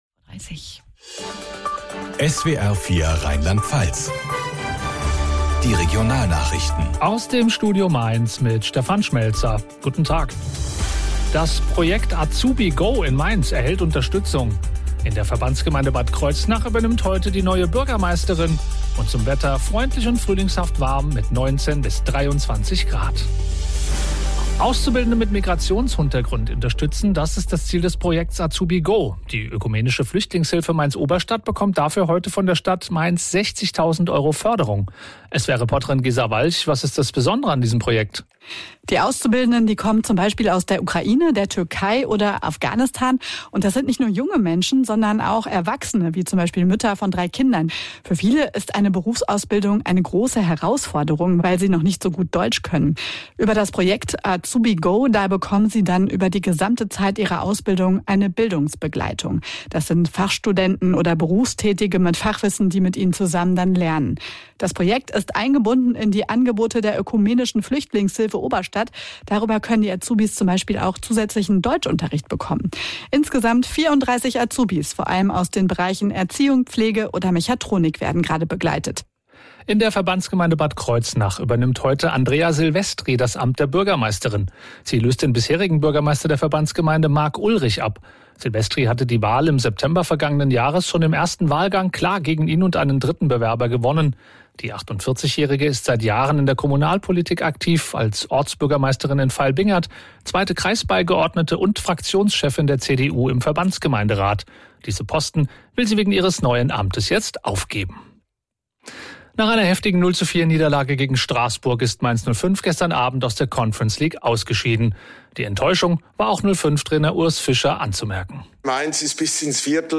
SWR Regionalnachrichten aus Mainz
radionachrichten.mp3